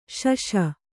♪ śaśa